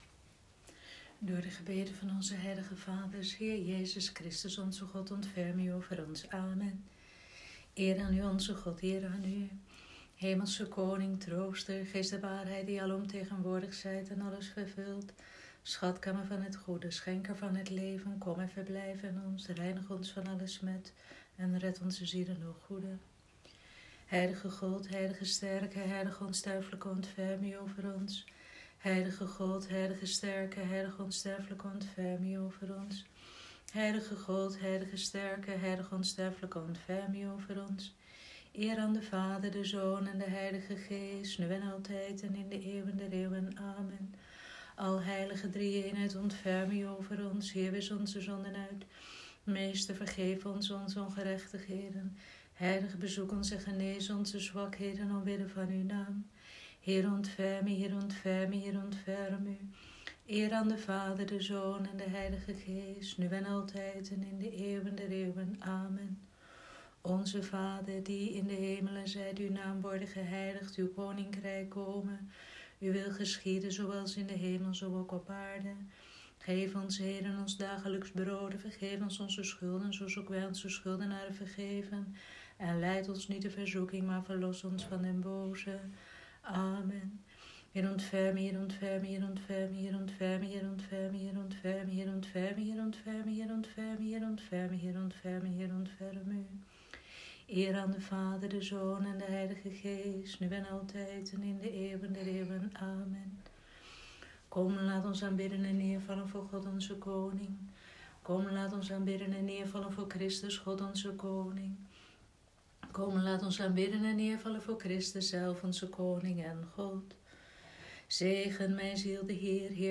Vespers Donderdagavond, 9 April 2020
Vespers-donderdagavond-9-april.m4a